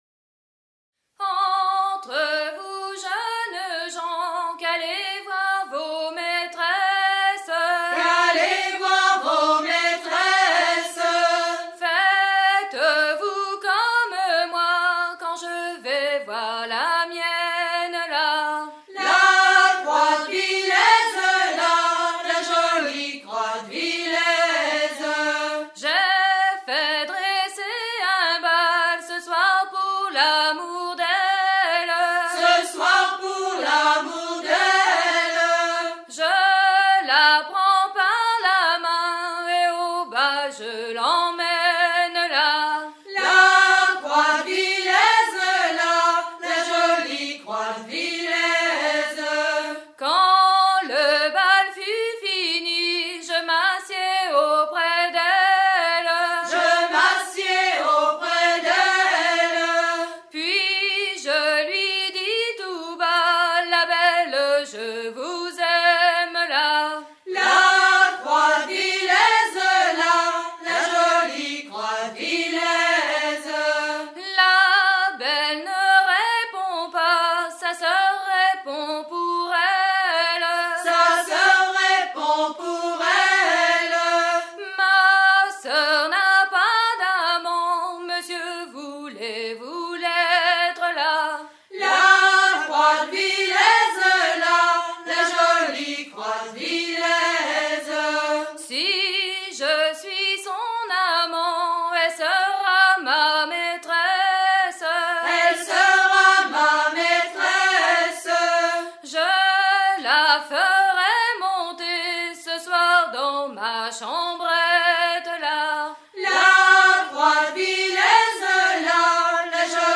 gestuel : à haler
circonstance : maritimes
Pièce musicale éditée